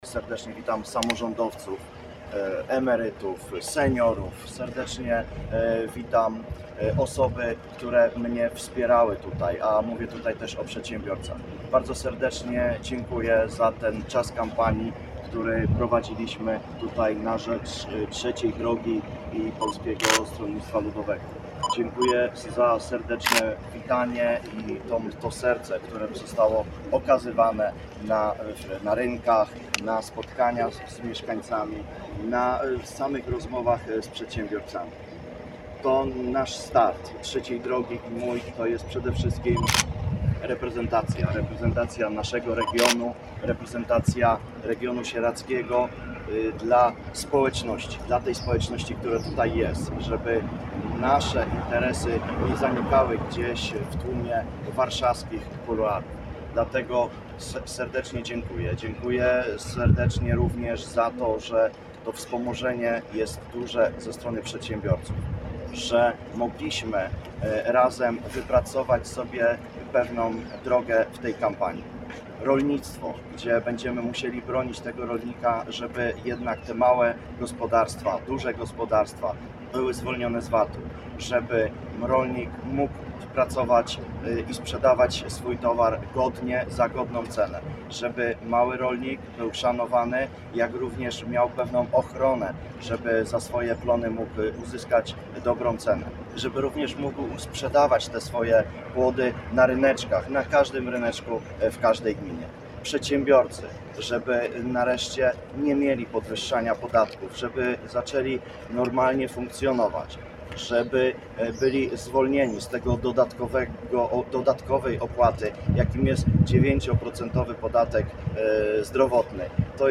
Starosta sieradzki Mariusz Bądzior podsumował kampanię do parlamentu Podsumowując swoją kampanię w rolniczym regionie, kandydat zwracał uwagę na konieczność wspierania tej gałęzi gospodarki. Posłuchaj całej konferencji: